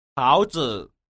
táozi